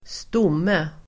Uttal: [²st'om:e]